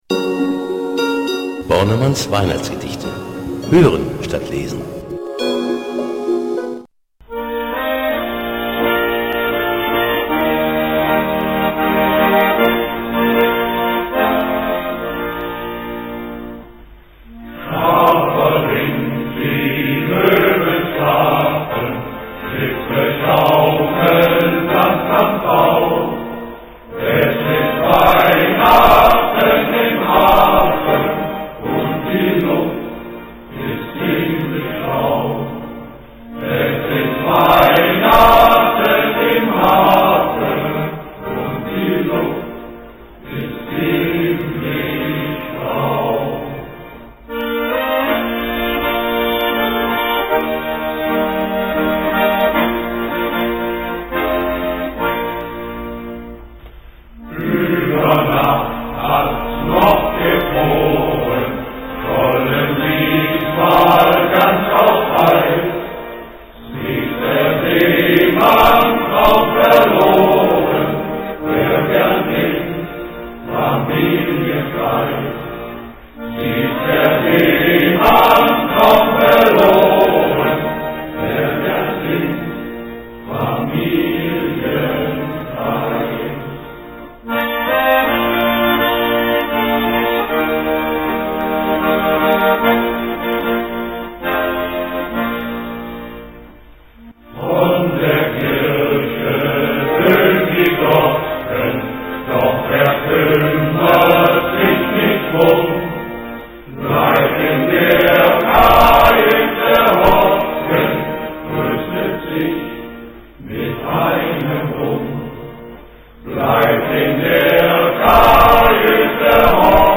H�rprobe Shanty Chor MK Frechen